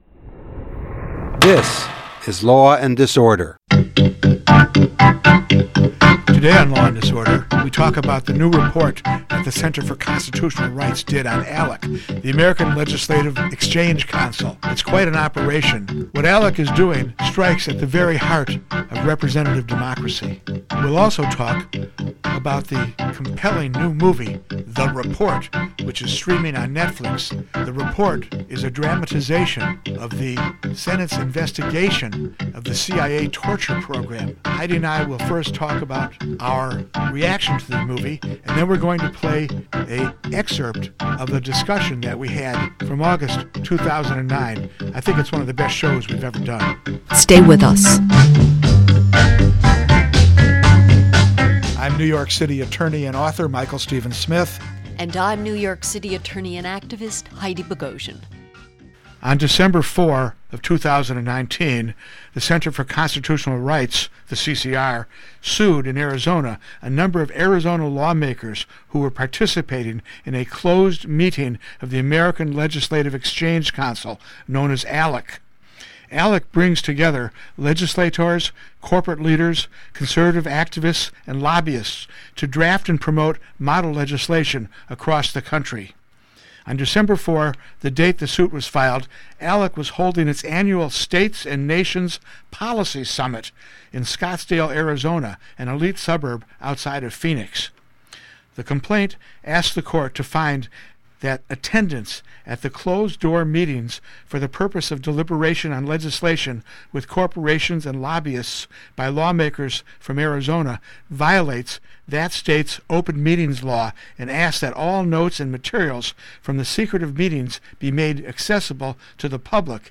Embassy Protectors Guest